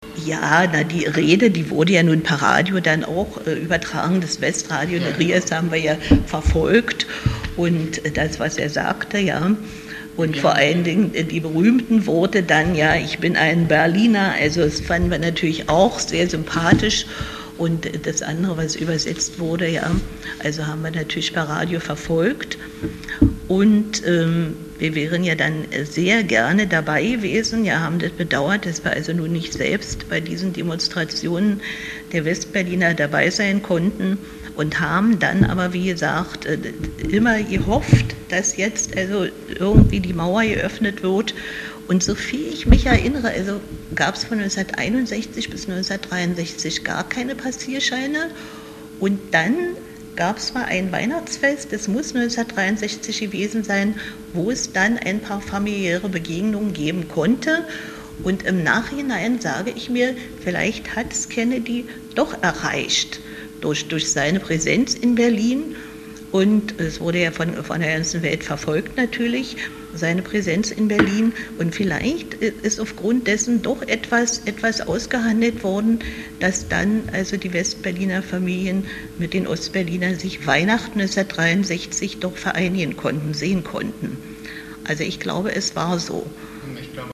Obwohl beide unzufrieden mit der politischen Führung der DDR waren, zeigten sie in einem Interview auf Fragen zum Kennedy-Besuch sehr unterschiedliche Meinungen.